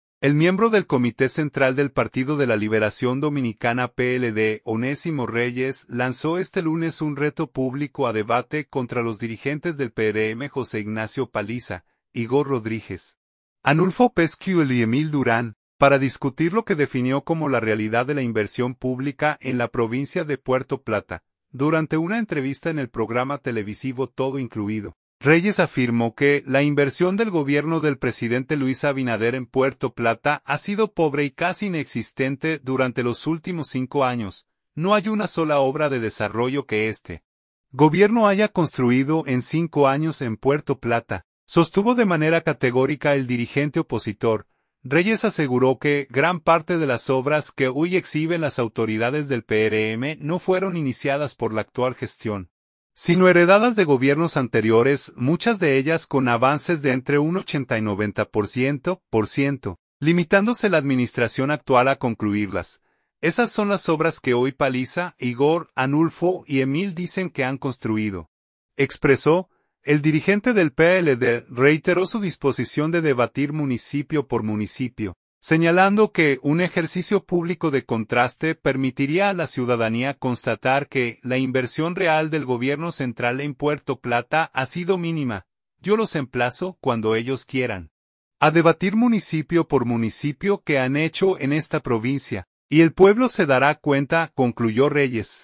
Durante una entrevista en el programa televisivo “Todo Incluido”